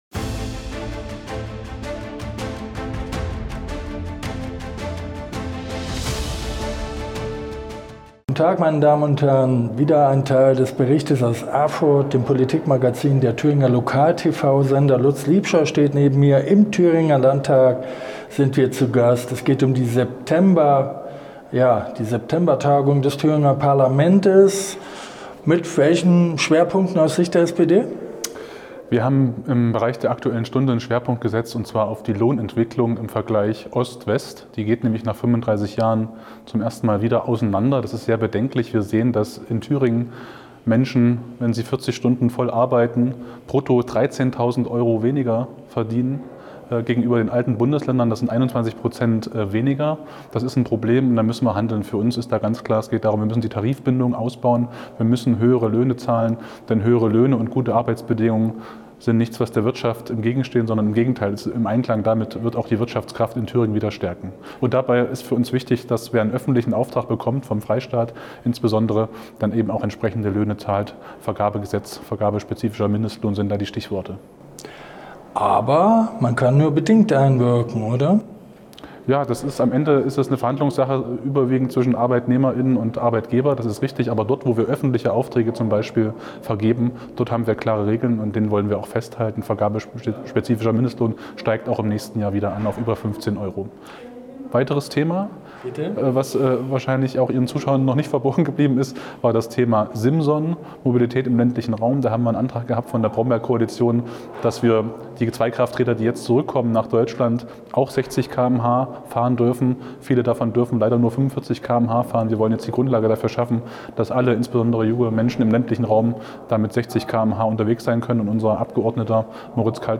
Aus der Ordensburg Liebstedt ordnen zwei regelm��ige Kommentatoren die Lage ein � analytisch, zugespitzt und ohne Besch�nigung.....